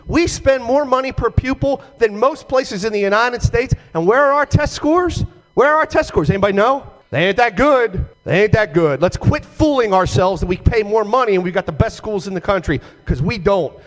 Moore had proposed delaying or cutting back portions of the plan, but House Democrats kept all spending in place.  House Minority Leader Jason Buckel argued in Annapolis that the plan is too costly for its investment return…